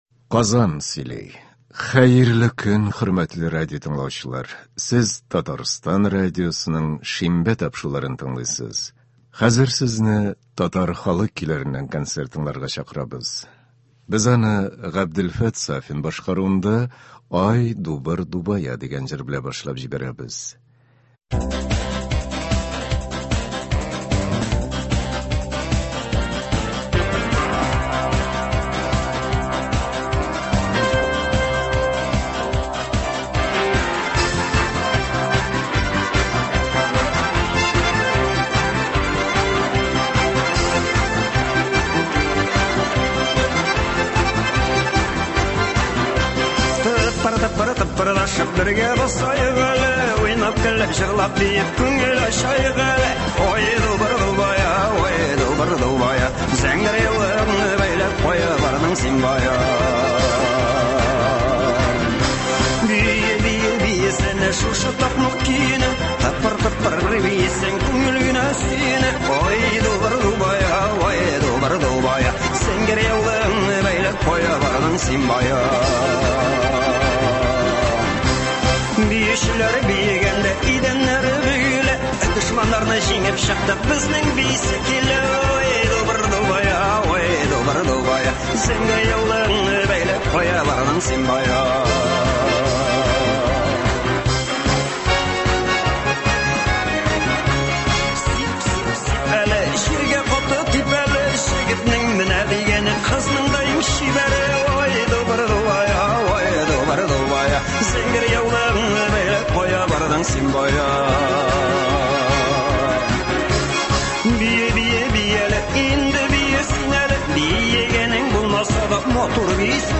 Татар халык көйләре (28.05.22)
Бүген без сезнең игътибарга радио фондында сакланган җырлардан төзелгән концерт тыңларга тәкъдим итәбез.